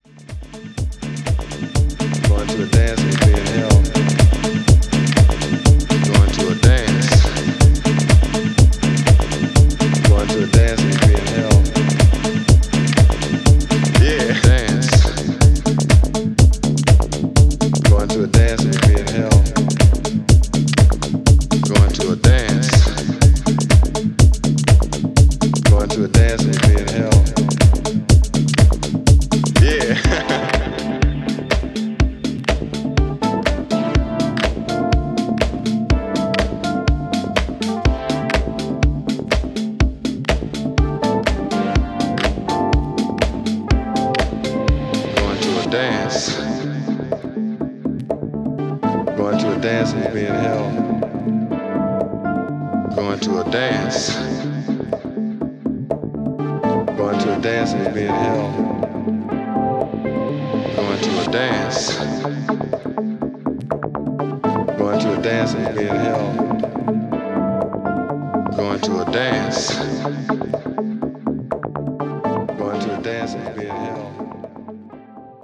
デトロイト方面のUSハウスからの影響色濃い一枚です。